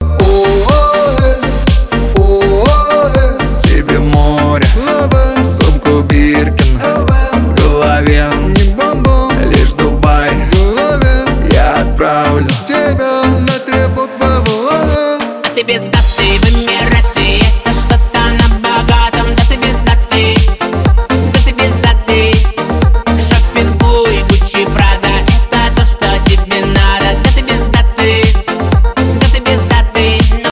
поп
танцевальные
веселые